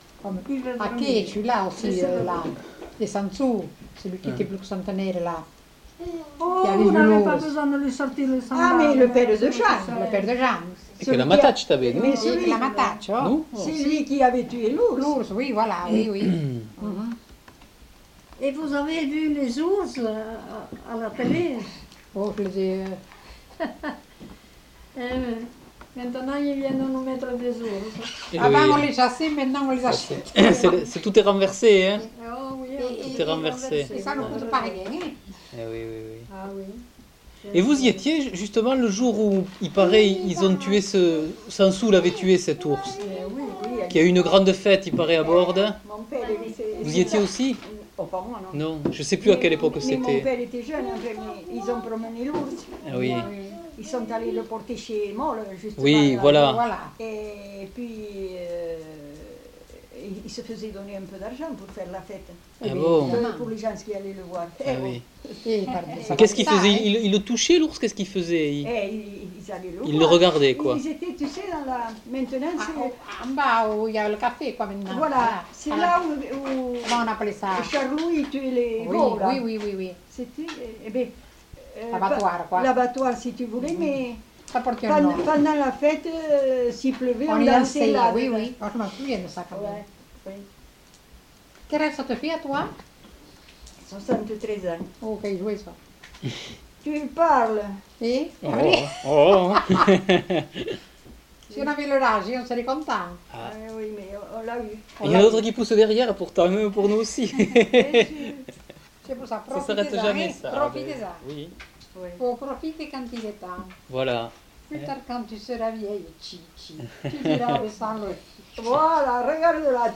Lieu : Montjoie-en-Couserans
Genre : témoignage thématique